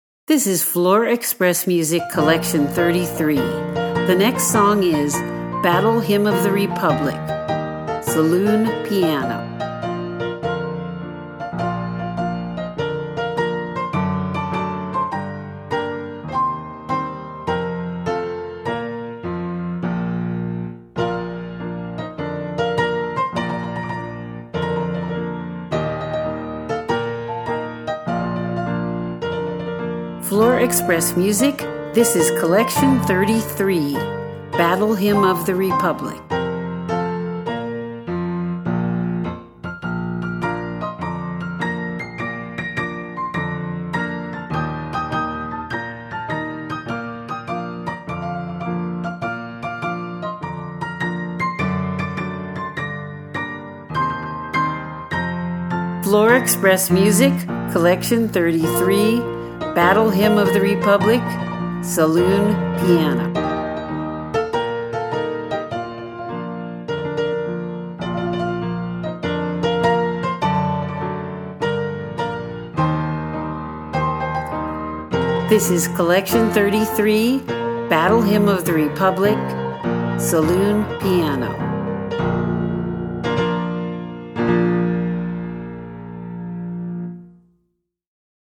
• Dixieland
• Piano